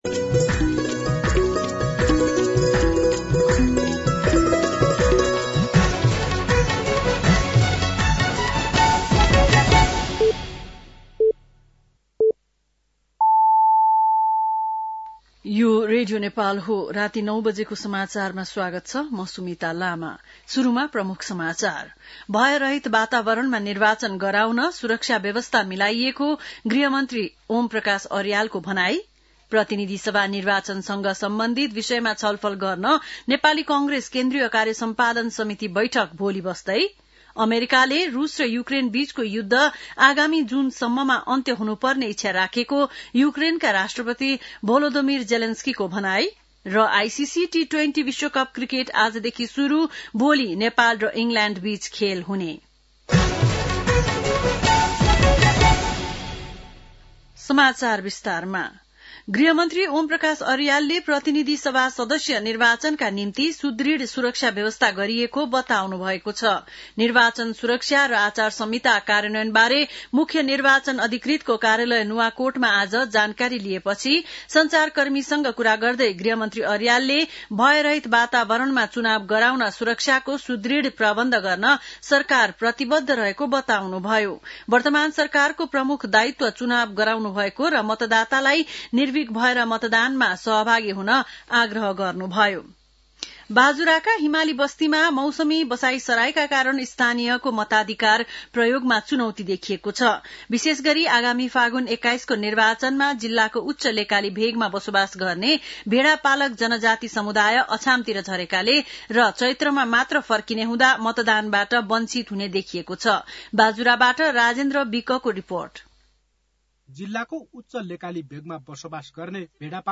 बेलुकी ९ बजेको नेपाली समाचार : २४ माघ , २०८२
9-pm-nepali-news-1-2.mp3